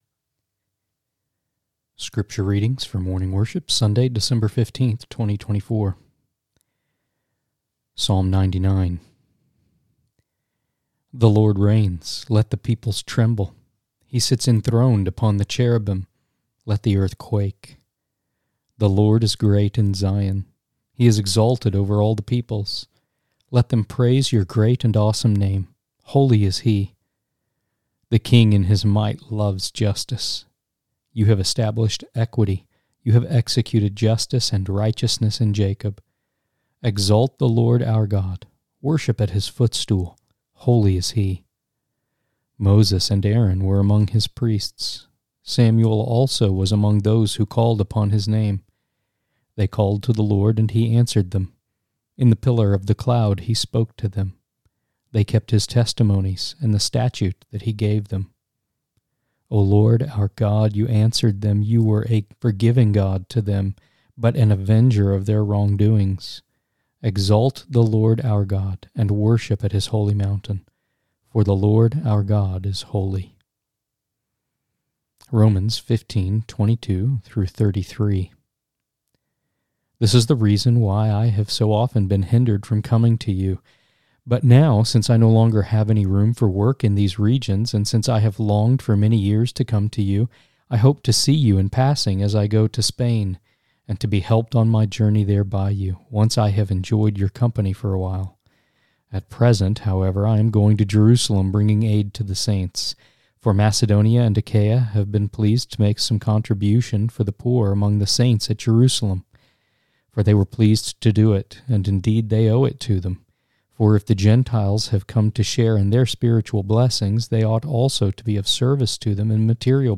Sermons and Lessons from All Saints Presbyterian Church (PCA) in Brentwood, TN.